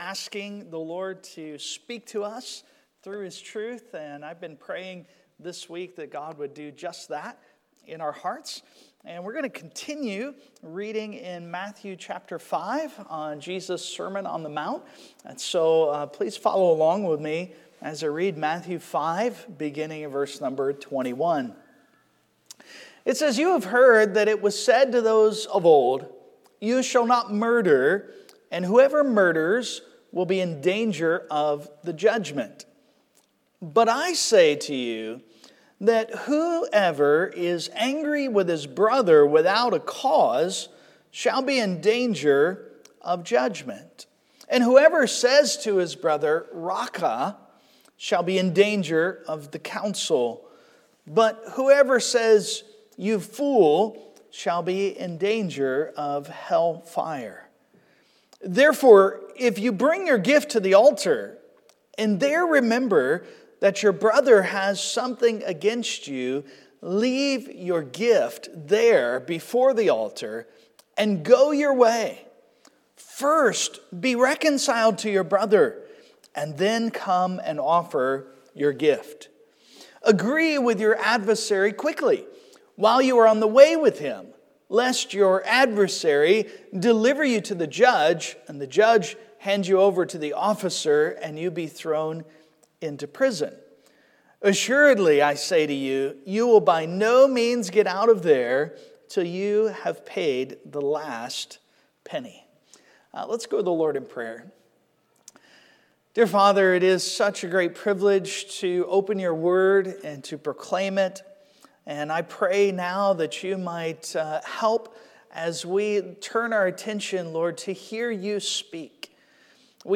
Passage: Matthew 5:21-26 Service Type: Sunday Morning Application Questions: 1.